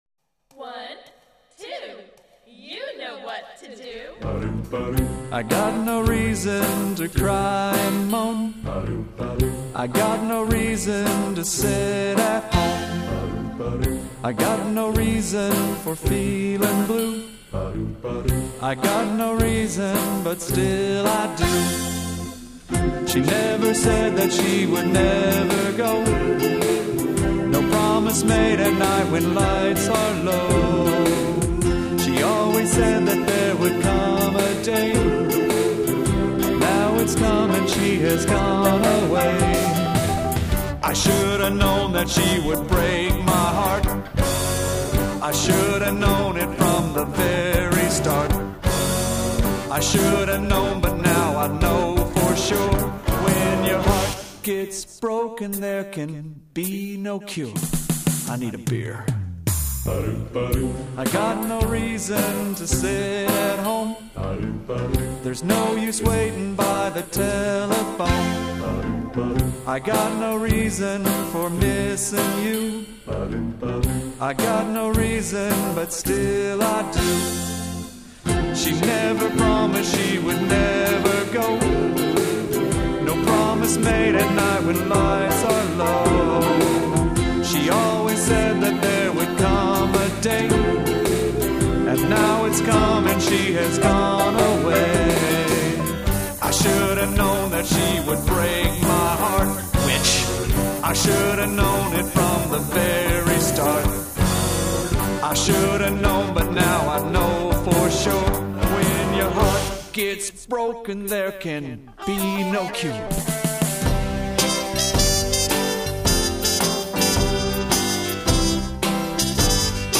sings the blues